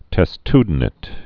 (tĕs-tdn-ĭt, -āt, -tyd-)